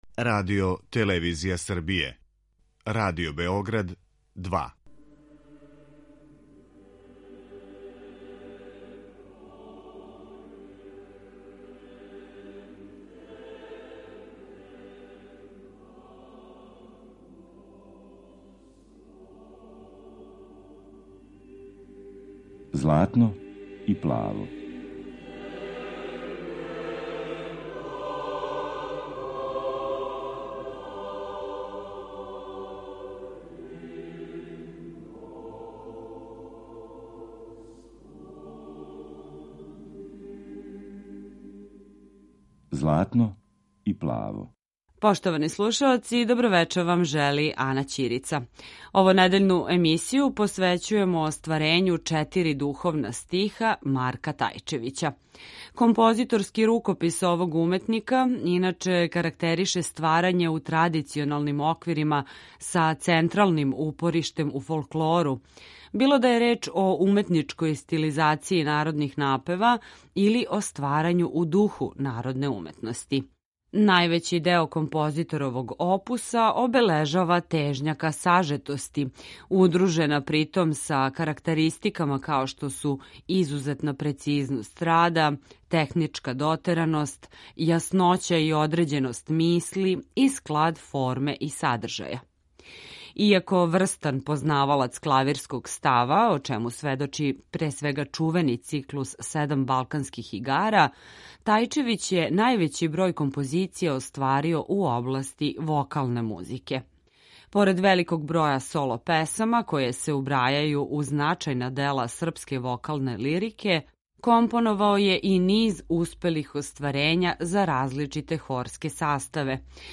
хорске литературе